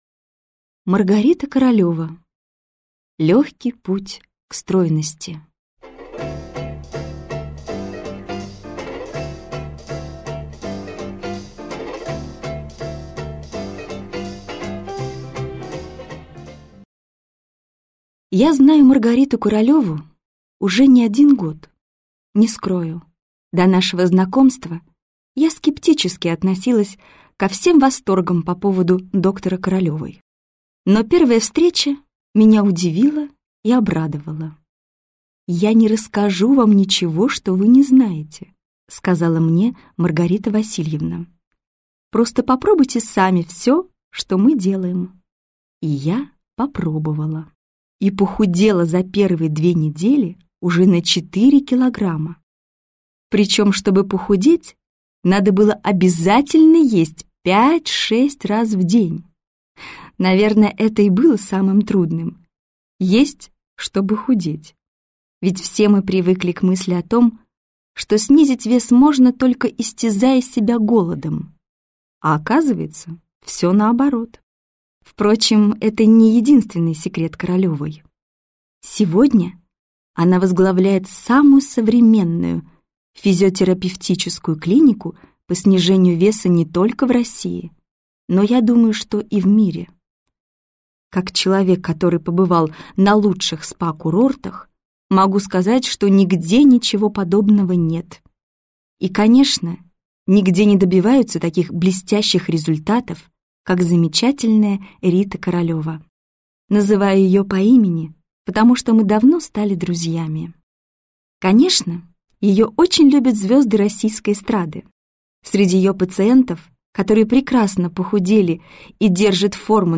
Aудиокнига Легкий путь к стройности. Похудеть навсегда!